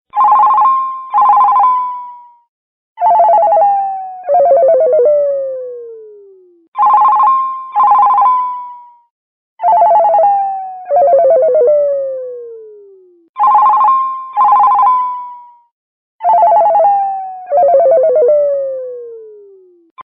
Kategori Mobiltelefon